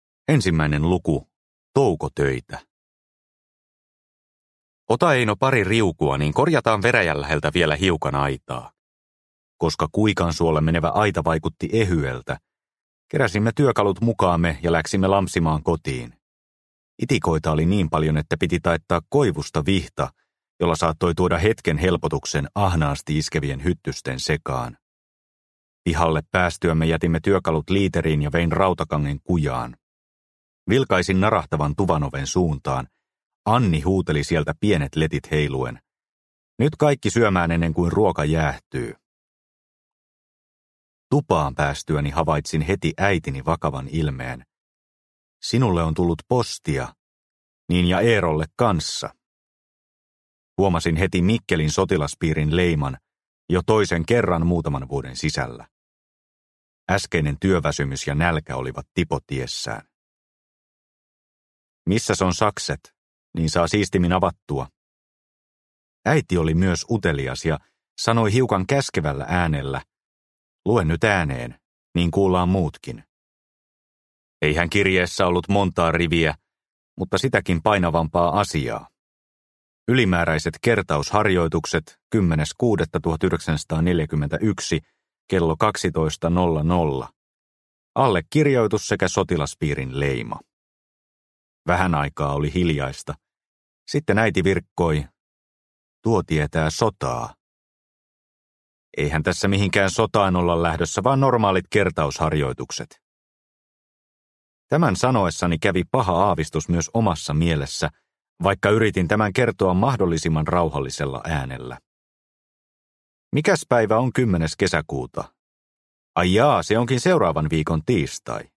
Lääkintäkersantti – Ljudbok – Laddas ner